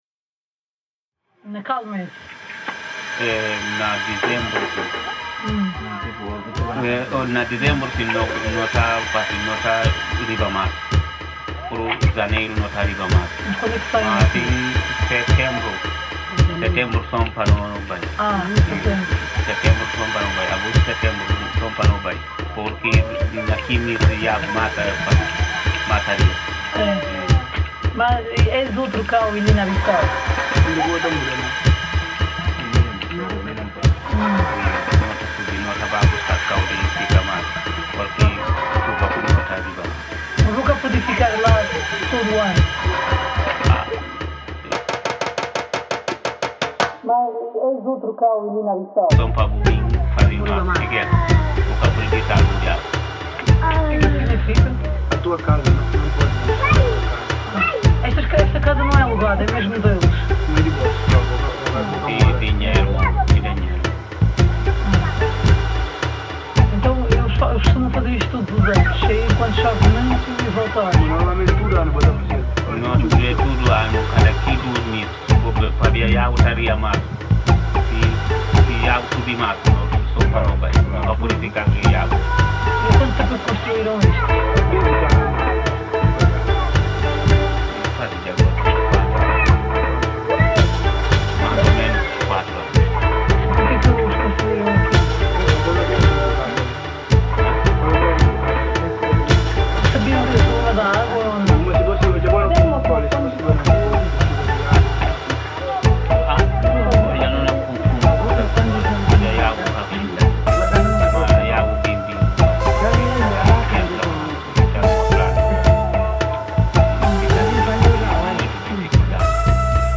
Guinea-Bissau migration recording reimagined